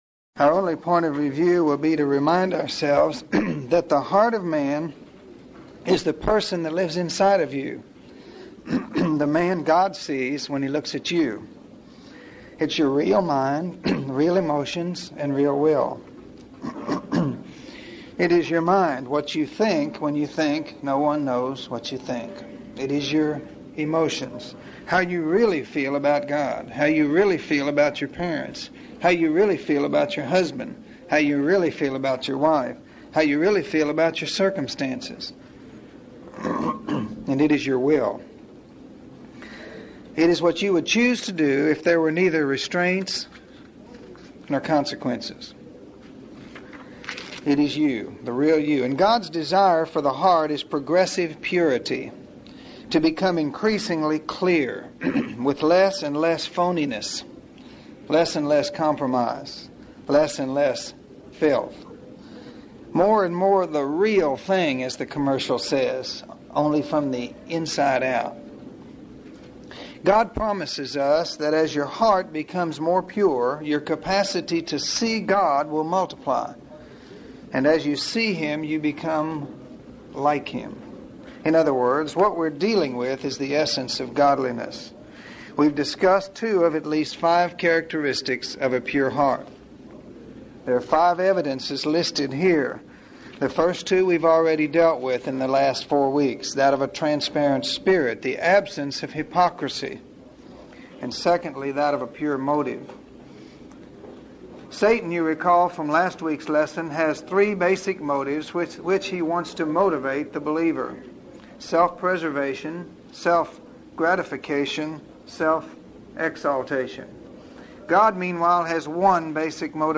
We cannot have a pure heart apart from having a clear conscience toward God. What does it mean to confess our sins and what is the motive behind our confession? In this lesson, we will answer four questions about confessing sin that may give us a better understanding of how God's heart breaks over our sin.